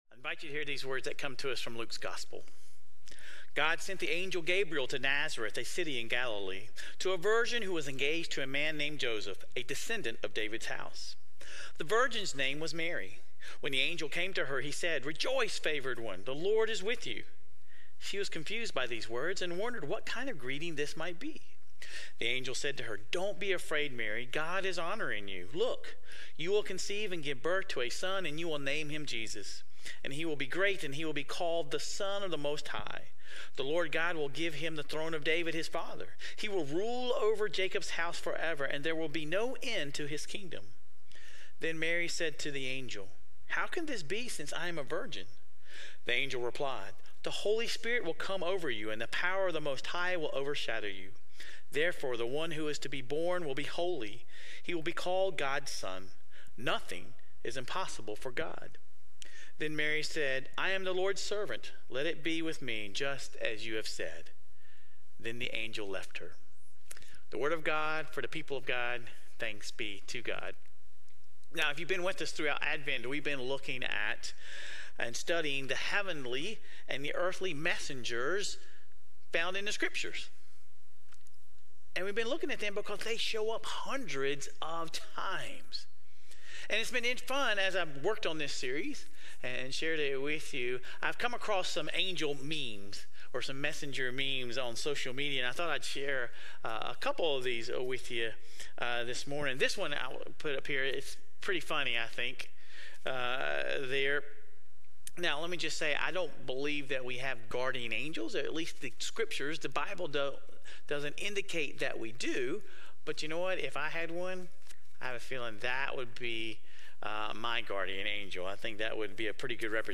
This week, we dive into Annunciations. Sermon Reflections: How can we cultivate a spirit of rejoicing, even in challenging circumstances, as Mary did?